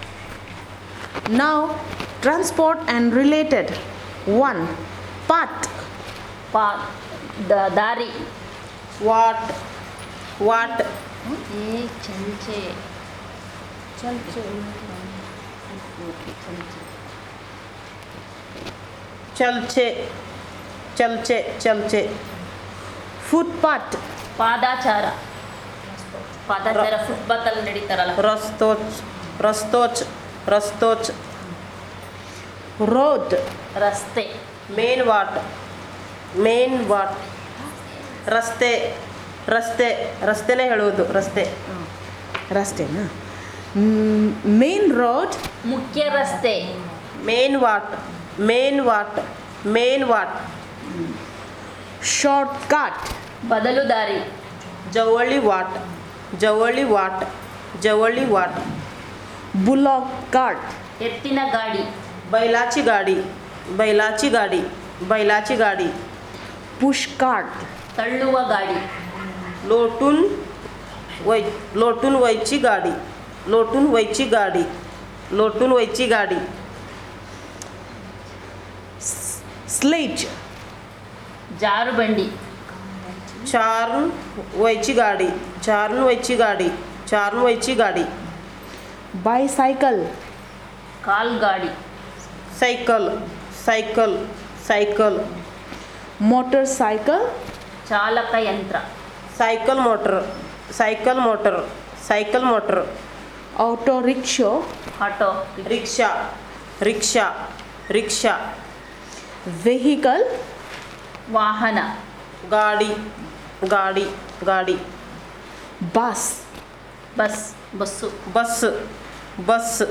Elicitation of words about Transport and related